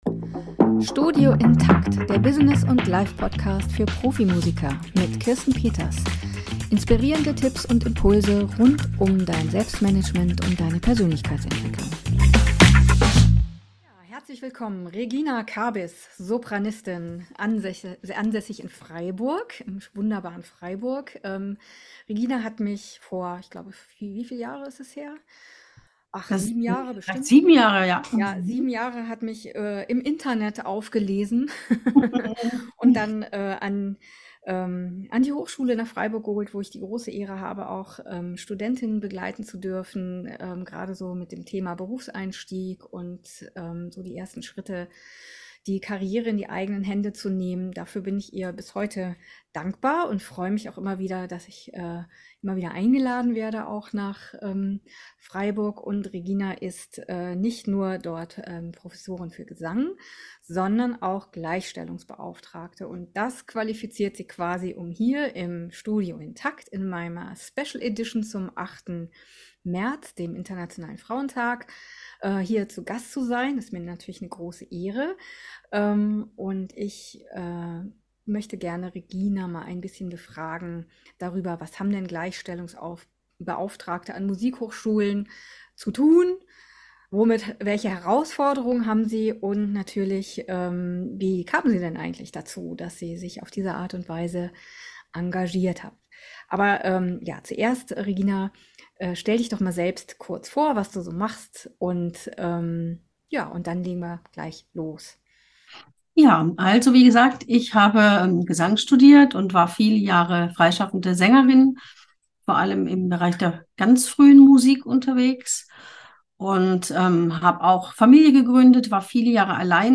Beschreibung vor 2 Jahren Im Interview